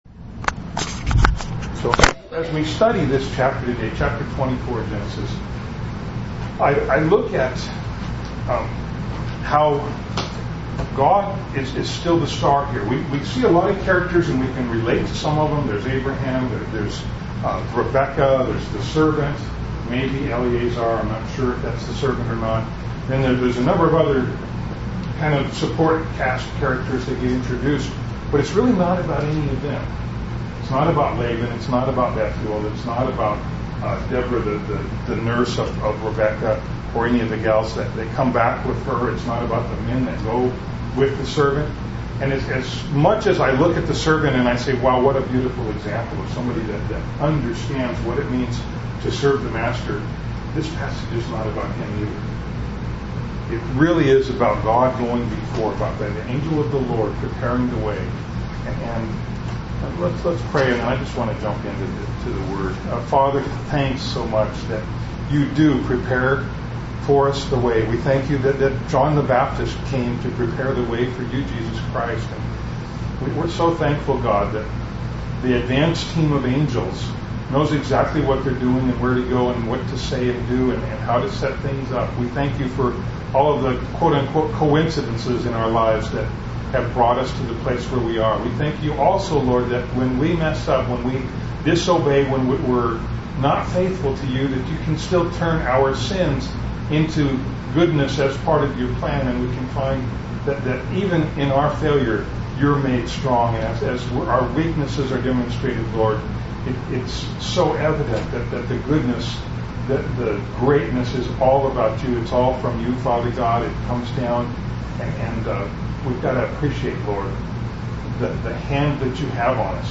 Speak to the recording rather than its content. A Bride For Isaac – Skykomish Community Church